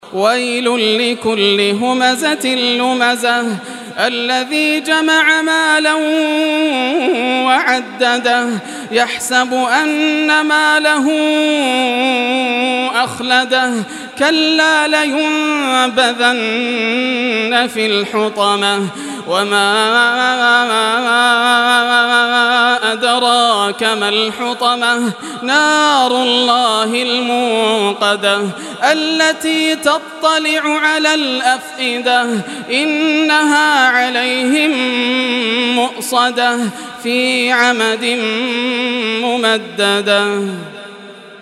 Surah Al-Humazah Recitation by Yasser al Dosari
Surah Al-Humazah, listen or play online mp3 tilawat / recitation in Arabic in the beautiful voice of Sheikh Yasser al Dosari.